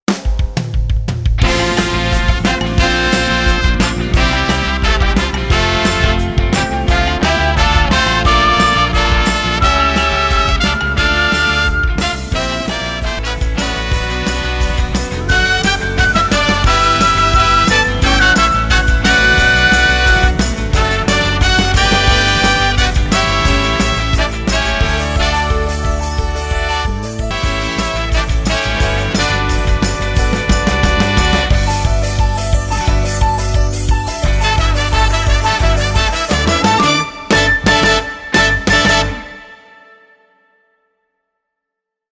難易度 分類 駈足176 時間 4分24秒
編成内容 大太鼓、中太鼓、小太鼓、シンバル、トリオ 作成No 333